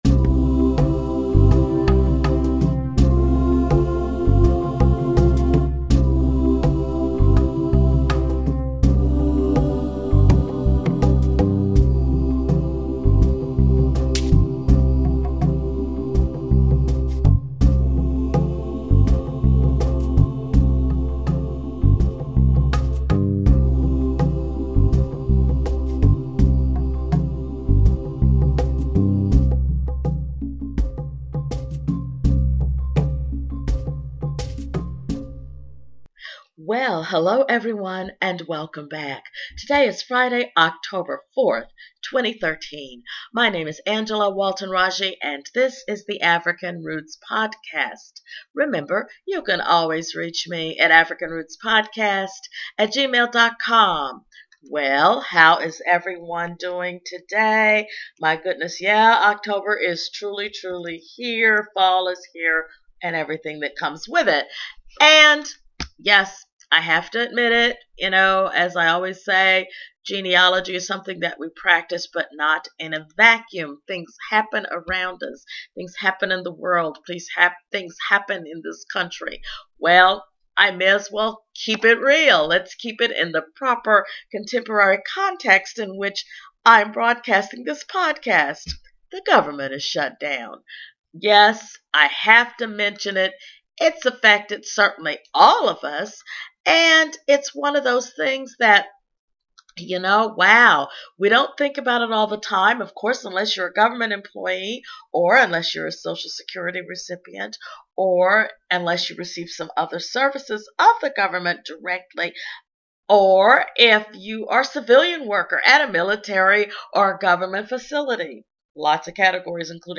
A special hello to you from Nashville Tennessee! I am here in Tennessee attending the National AAHGS conference!